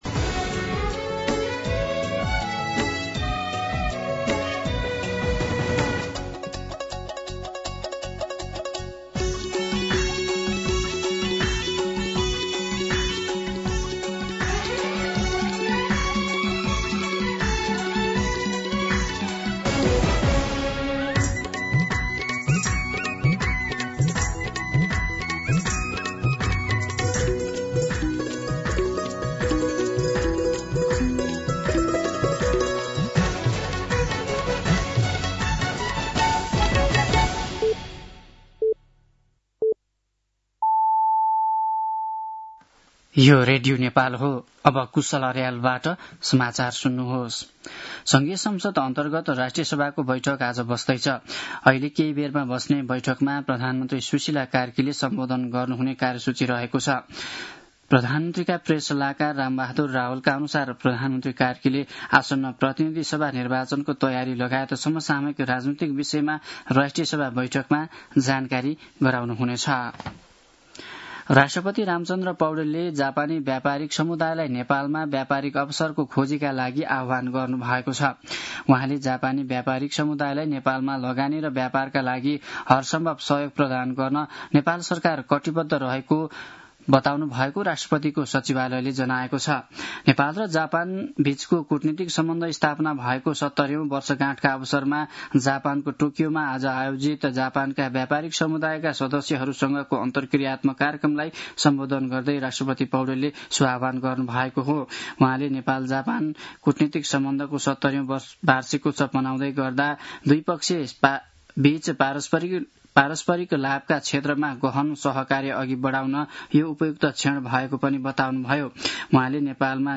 दिउँसो १ बजेको नेपाली समाचार : १९ माघ , २०८२
1pm-News-19.mp3